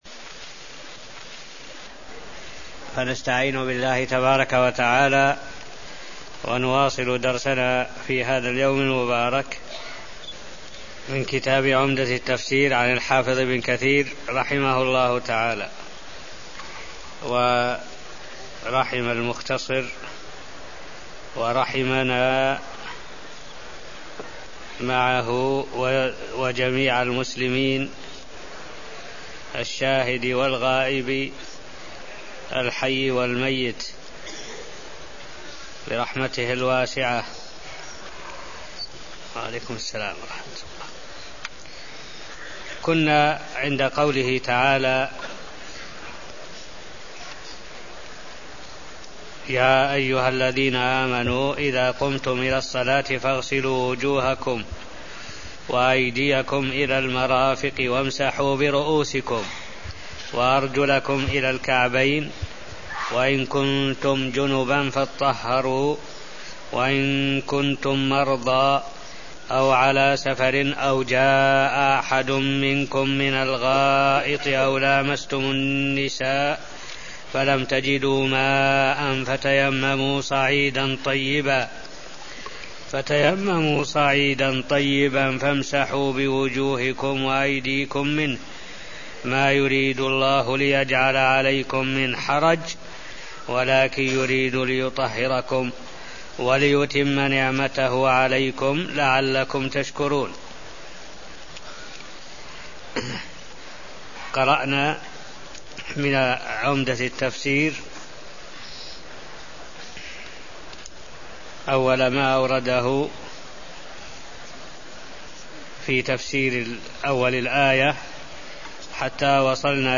المكان: المسجد النبوي الشيخ: معالي الشيخ الدكتور صالح بن عبد الله العبود معالي الشيخ الدكتور صالح بن عبد الله العبود تفسير سورة المائدة آية 6 (0229)